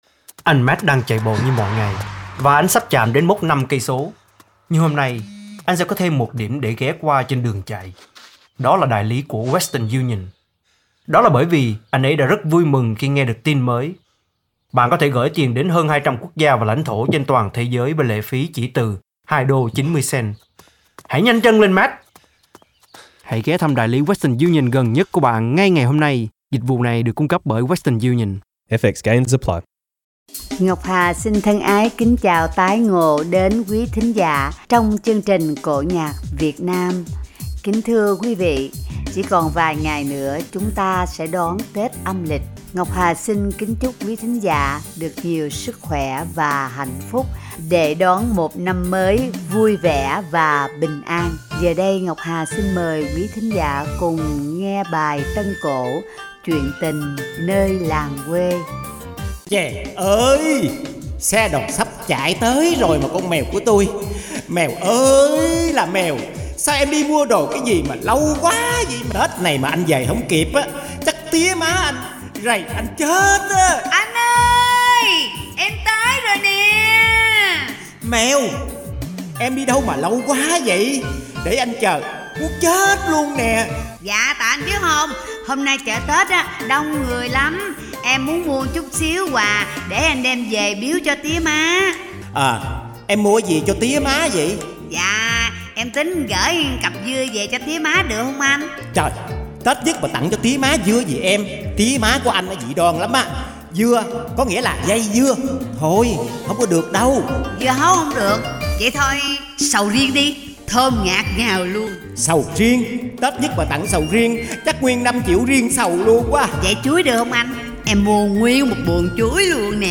Tiết mục Cổ Nhạc Việt Nam tại Úc, mang đến quí vị câu chuyện vui nhộn và ý nhị về việc kiêng cử, nhân trong những ngày Tết nhất sau đây.